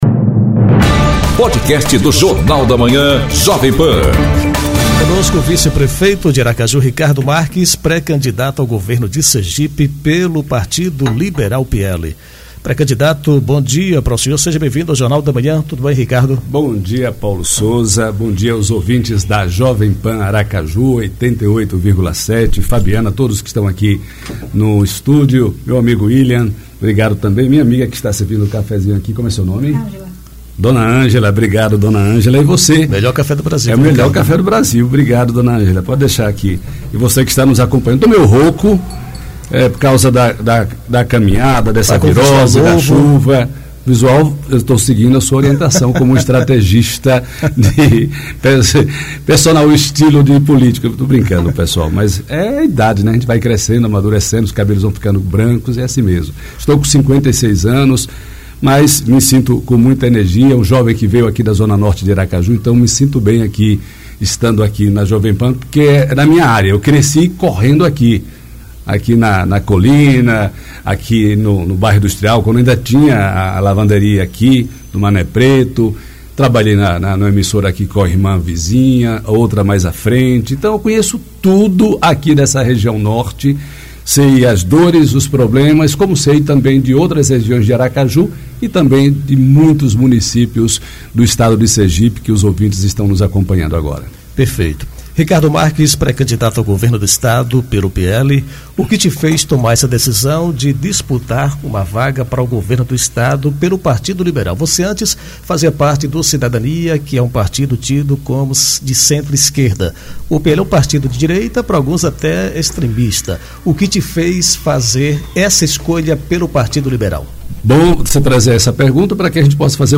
Entrevista com o vice-prefeito de Aracaju, Ricardo Marques, pré-candidato a governador de Sergipe pelo PL. Ele fala sobre planos e projetos para o estado.